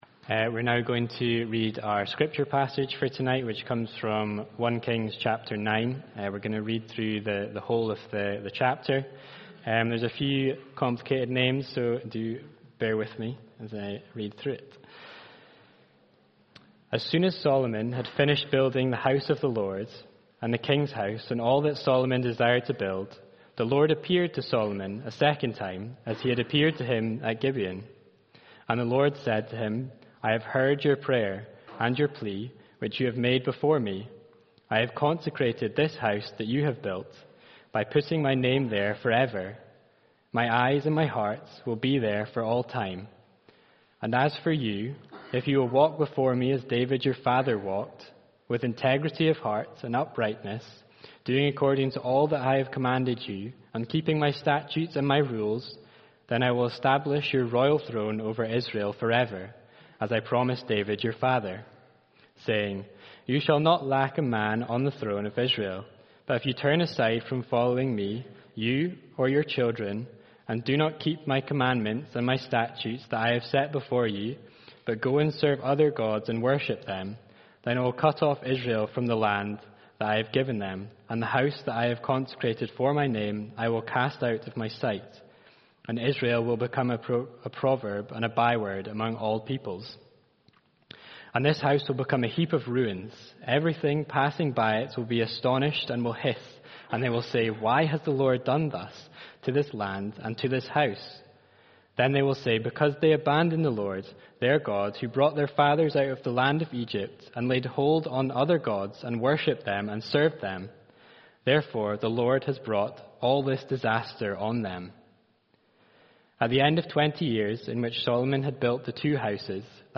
Evening Sermon from 28 September